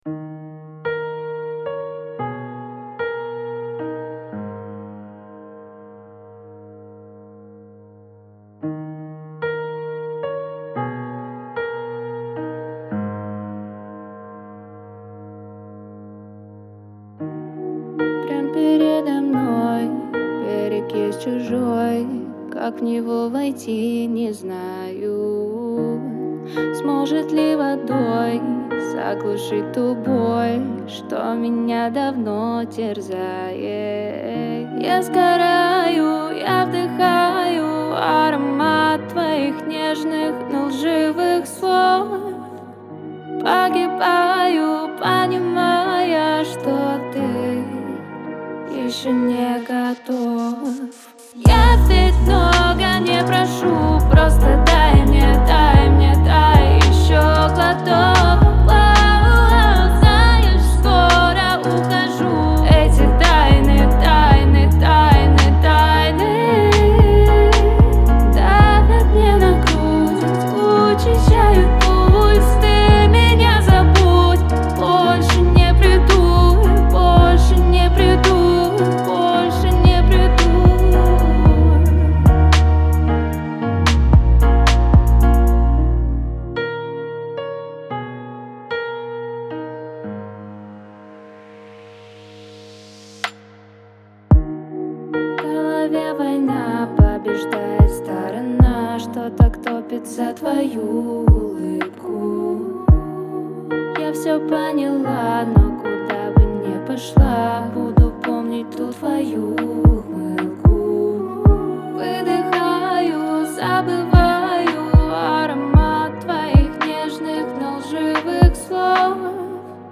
это яркая композиция в жанре поп